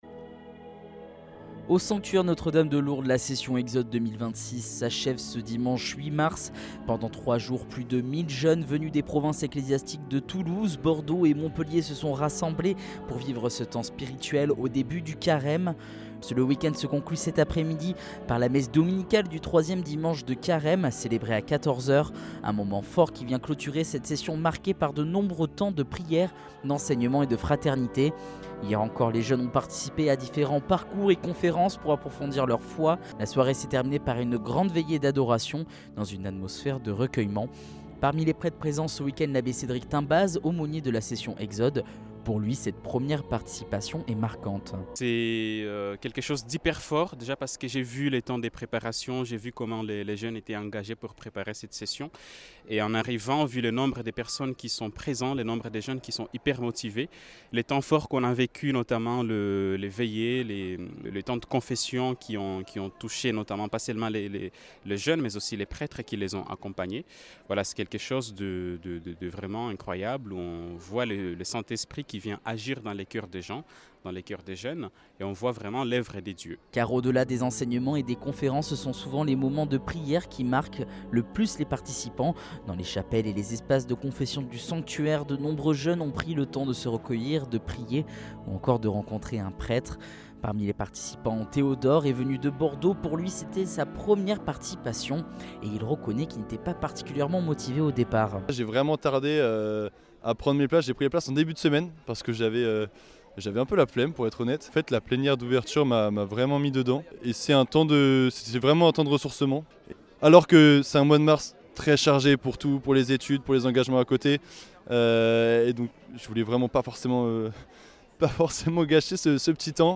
Reportage Exode 2026 du 08 mars